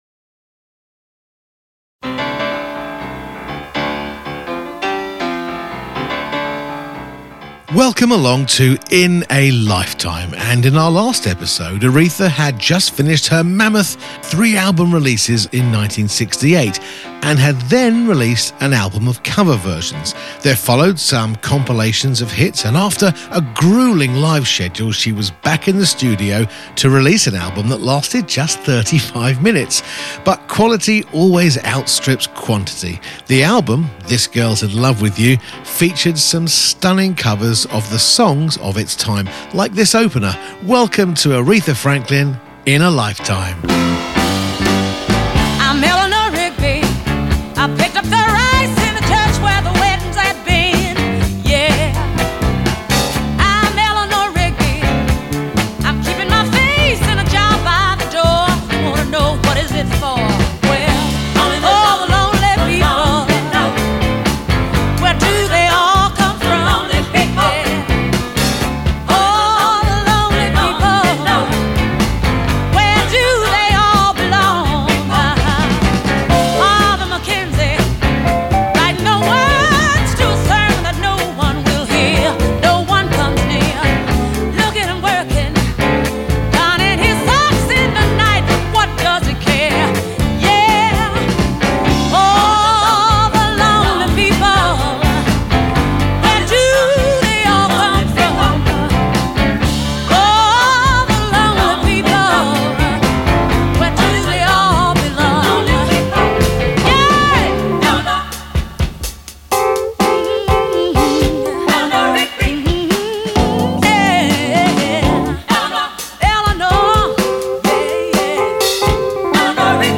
Our most celebrated Artists from the worlds of Music, Movies, TV and beyond Join the late Benny Green as he chats with one of the songwriting and musical ic ...